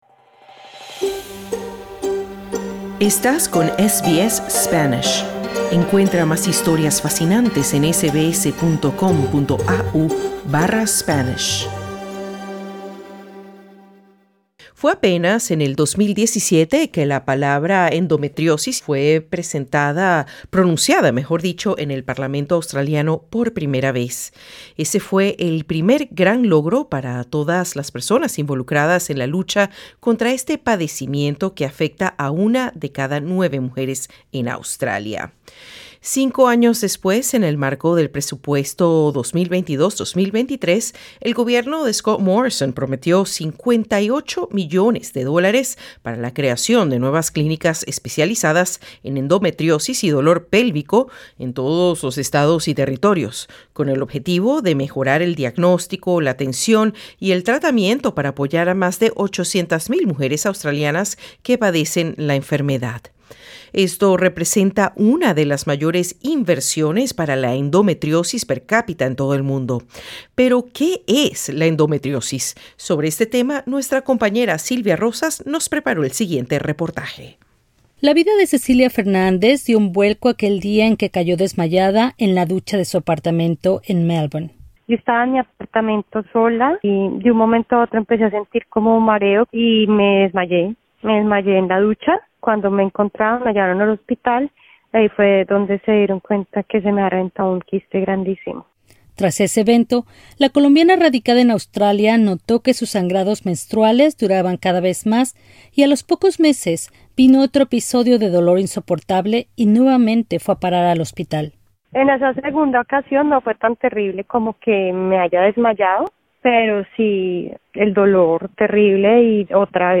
Presiona en el ícono que se encuentra en esta imagen para escuchar el reporte completo.